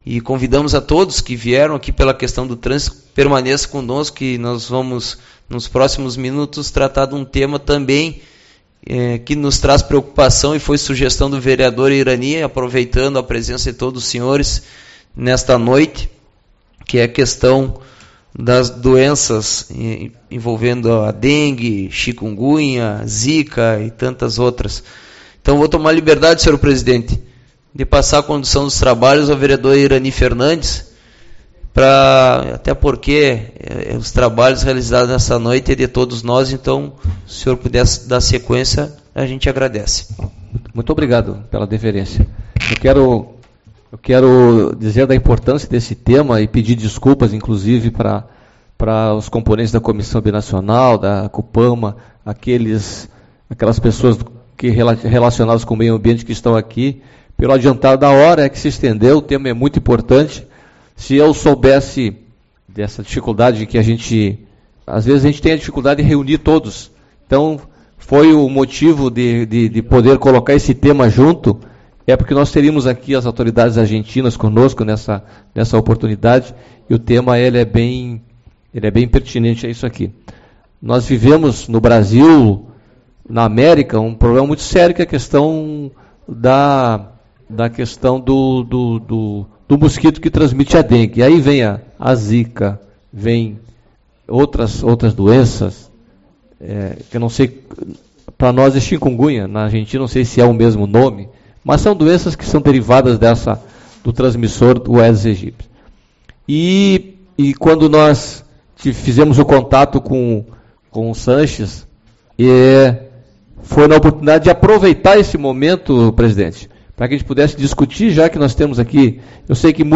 10/03 - Audiência Pública - Acidentes Argentinos e Aedes Aegypti